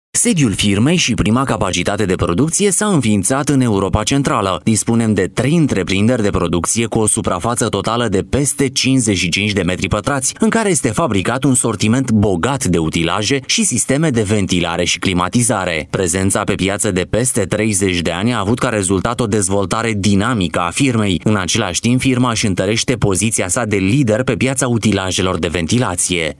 Sprecher rumänisch für TV / Rundfunk / Industrie / Werbung.
Sprechprobe: Werbung (Muttersprache):
Professionell rumanian voice over artist